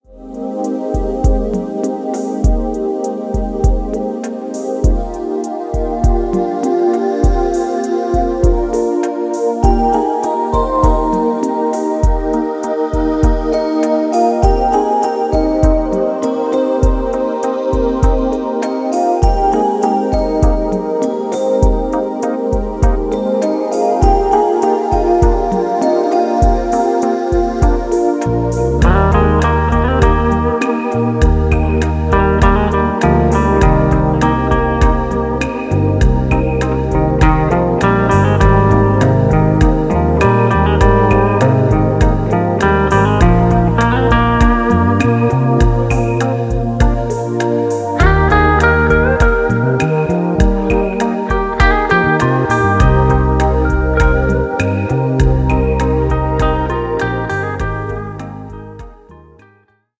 Instrumental
Dabei greift er nicht nur auf die E-Gitarre zurück
akustischen Gitarre